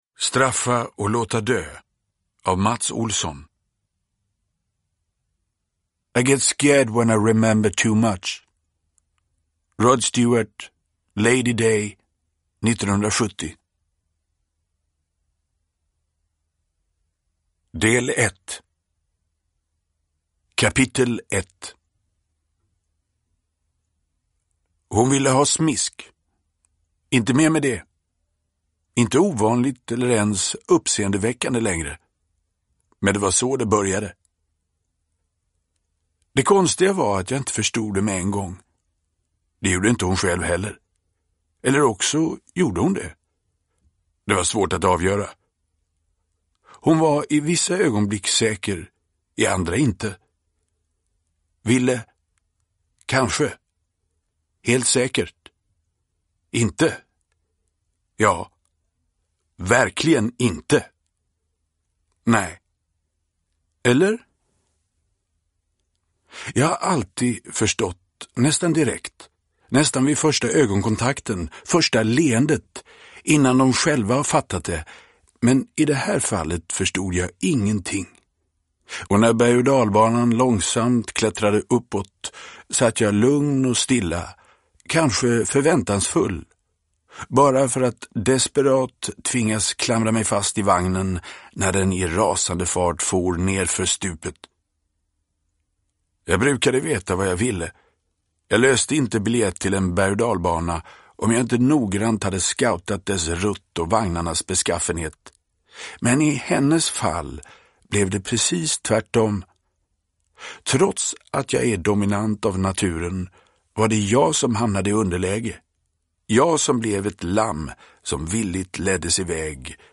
Uppläsare: Magnus Roosmann